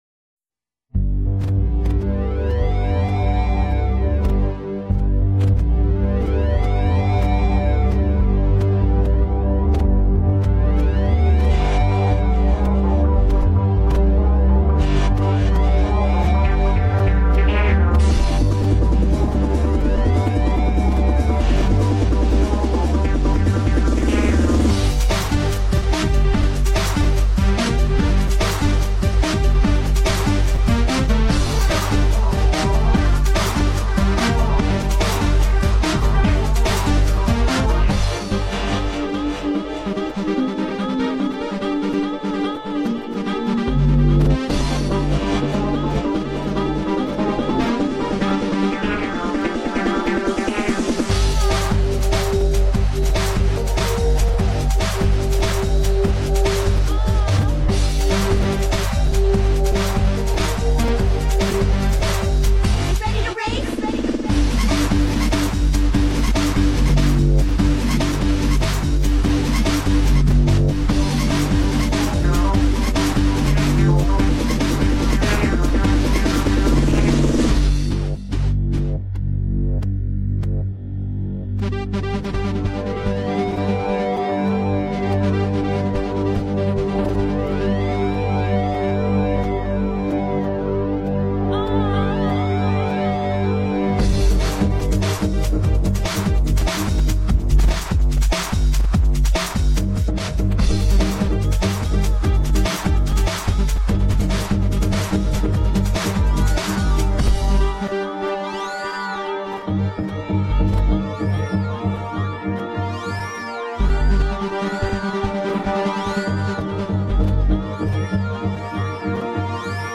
(PS2 Version)